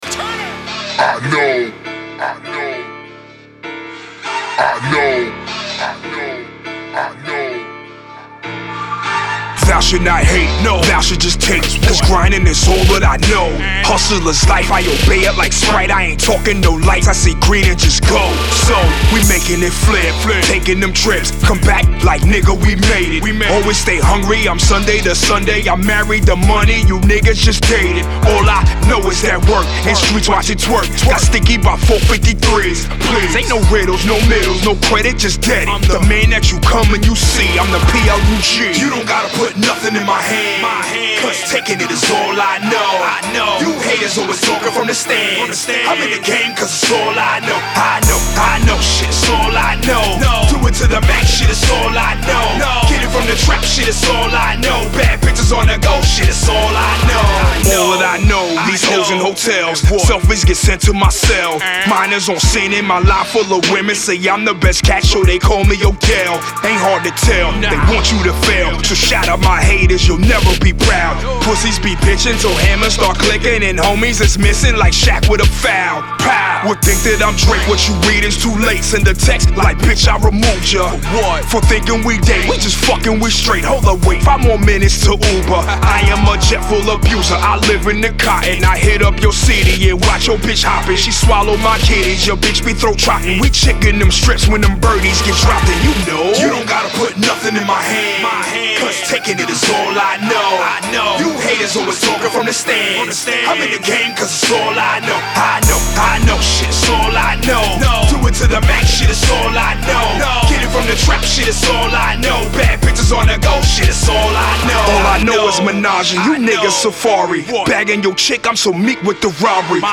Strong, Precise and Hot As FCK!